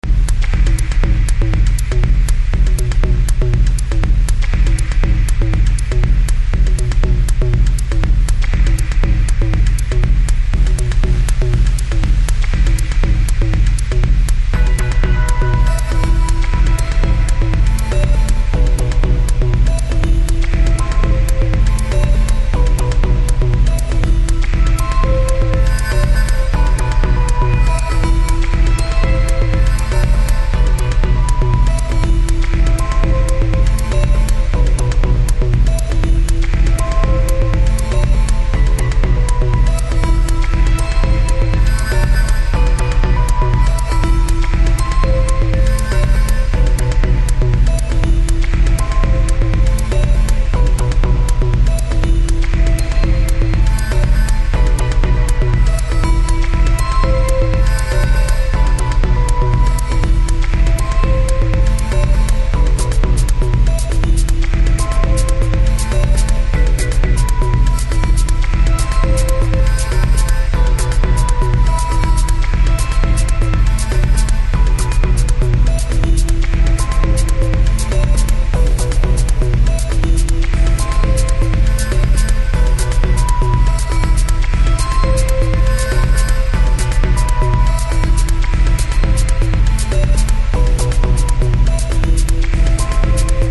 outstanding darker techno tracks